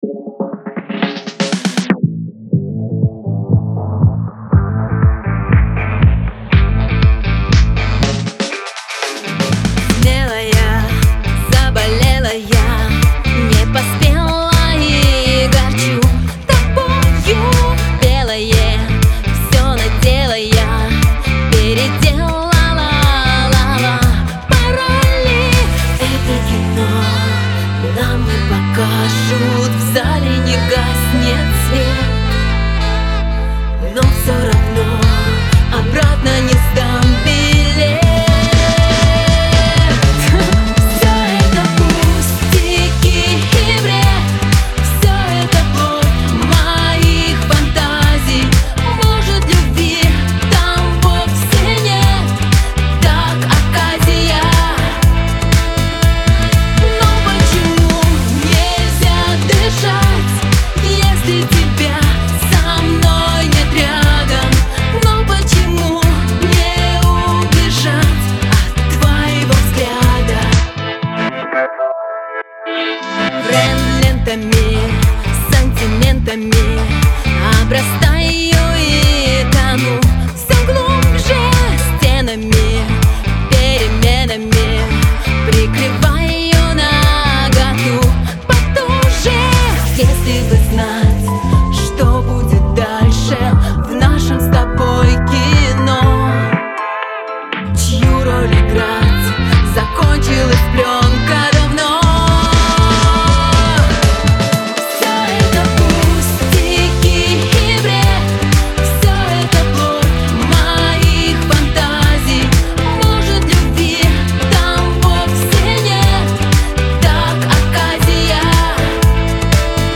Категория: POP